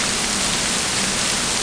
A_SPRAY.mp3